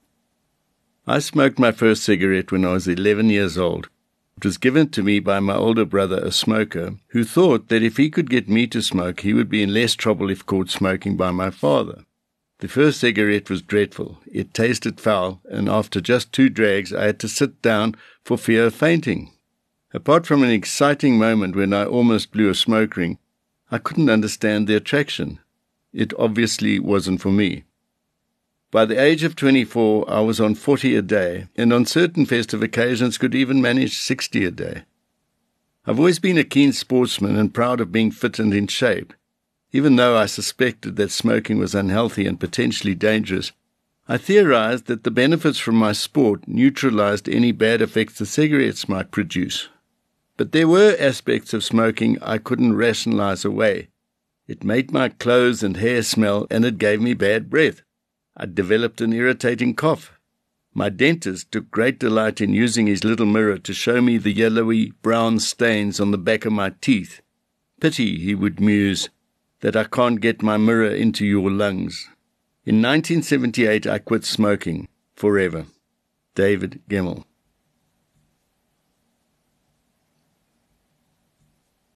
Audiobook Samples from Solid Gold